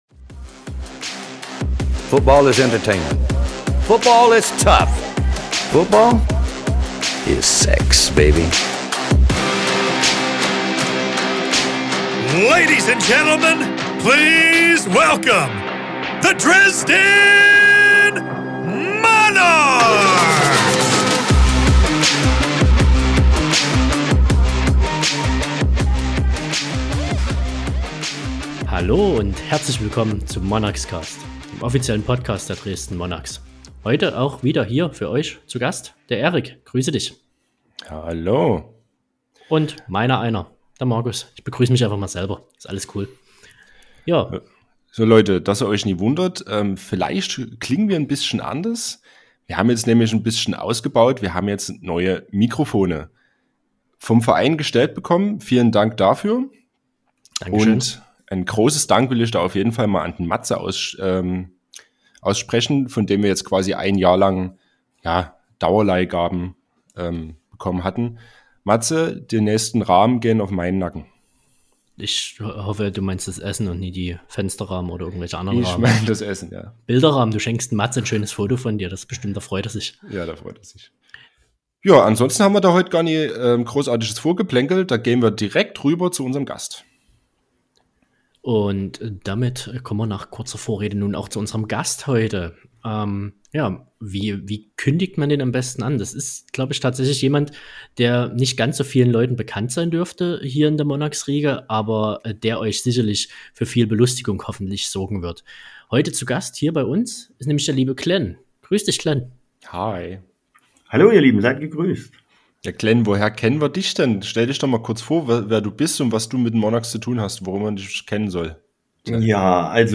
Wir informieren euch in der GFL freien Zeit einmal monatlich mit einer Folge, in welcher wir euch über aktuelle News auf dem Laufenden halten. Außerdem wird immer ein interessanter Gast in einem Interview Rede und Antwort stehen. In dieser Folge gibt es Klatsch und Tratsch rund um unsere Imports.